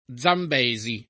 vai all'elenco alfabetico delle voci ingrandisci il carattere 100% rimpicciolisci il carattere stampa invia tramite posta elettronica codividi su Facebook Zambesi [ +z amb $@ i ] (ingl. Zambezi [ @ ämb & i @ i ] o Zambesi [id.]; port. Zambeze [ @9 mb $@ ë ]) top. m. (Afr.)